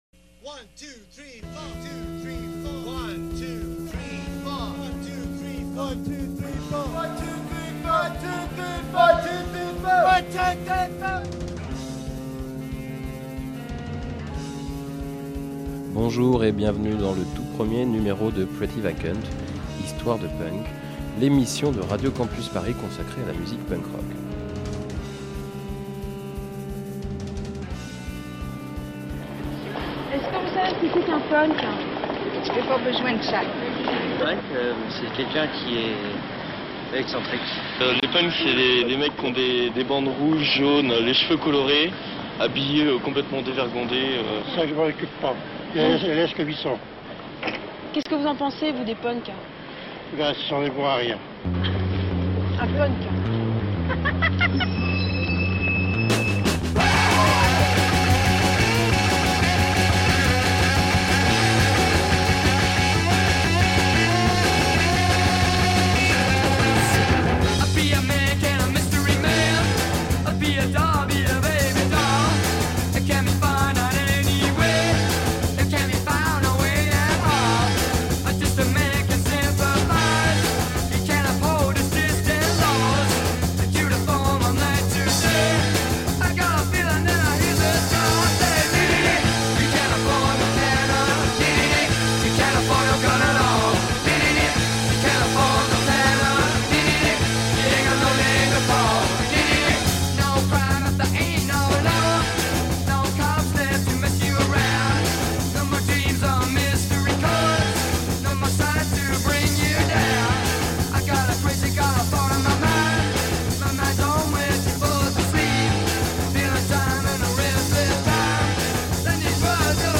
Il y aura des chansons "manifestes". Il y aura des micros-trottoirs. Il y aura le traitement cliché des médias français.